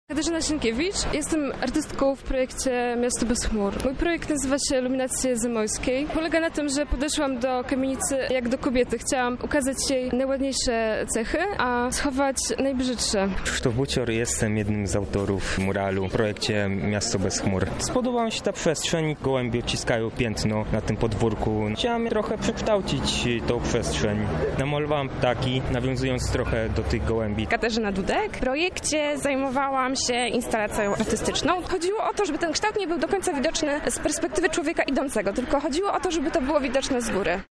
Oto co niektórzy z nich mówili o swoich projektach.